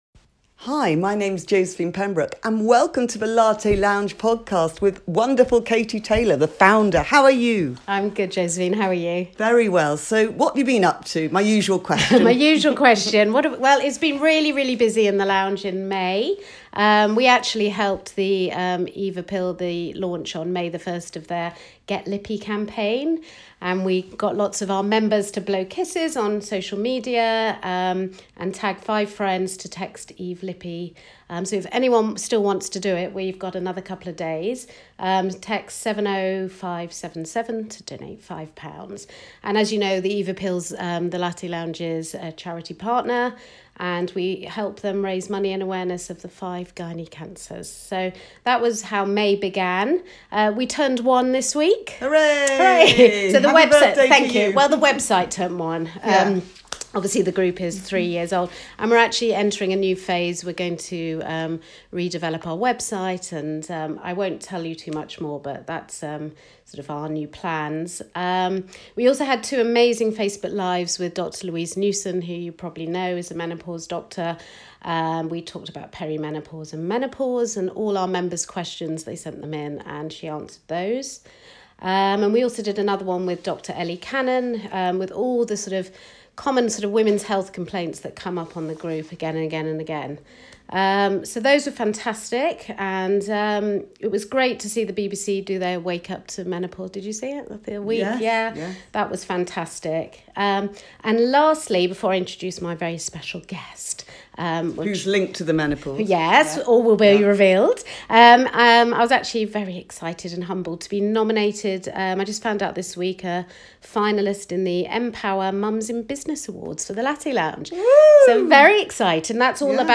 Loose woman Andrea McLean is the guest on this month's Latte Lounge podcast. Andrea tells us about her up and coming show Confessions of a Menopausal Woman, hot on the heels of her best selling book.